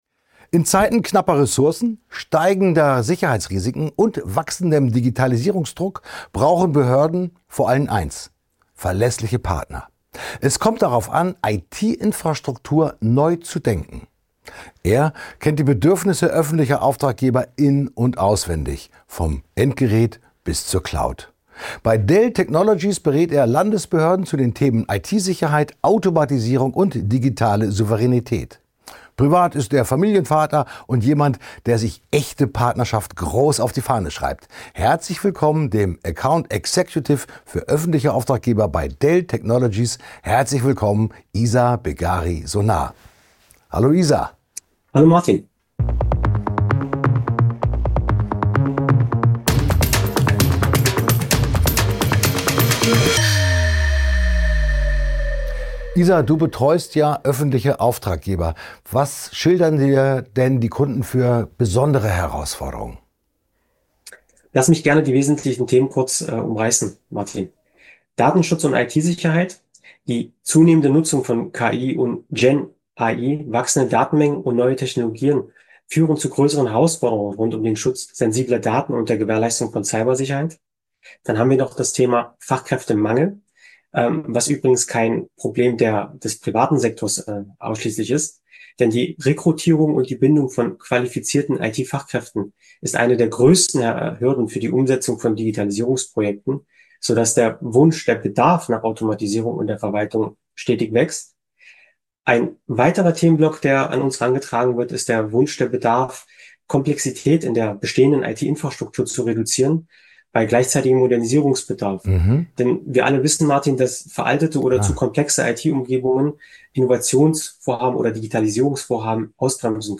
Auf einen Kaffee mit... - der Expert*innen Talk für Digitalisierung im Bildungsbereich